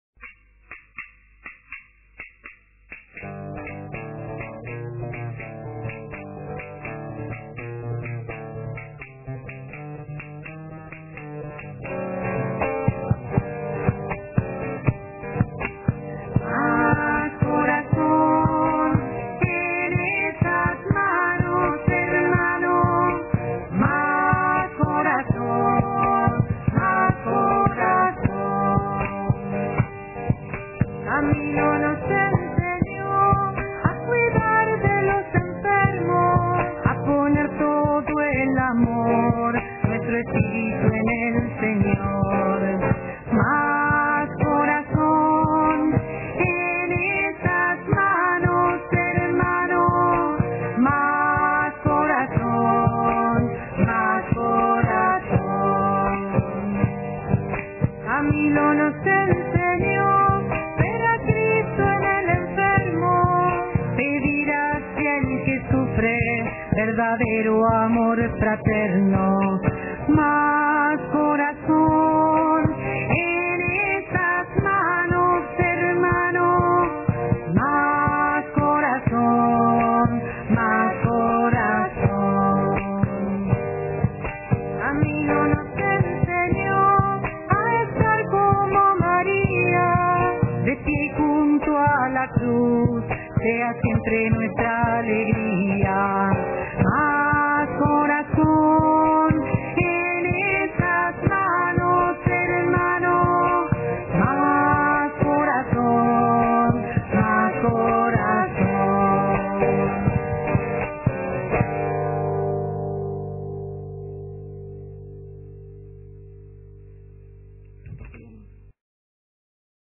Más Corazón, Hermanos (Cueca) MÁS CORAZÓN, EN ESAS MANOS, HERMANO MÁS CORAZÓN, MÁS CORAZÓN.